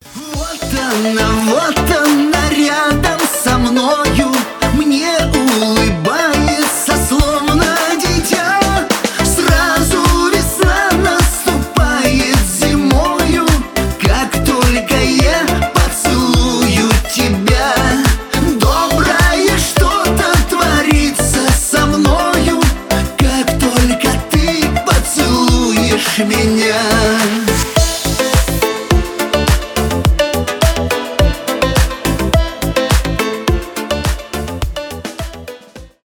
шансон
эстрадные